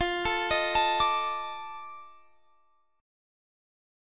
Listen to F13 strummed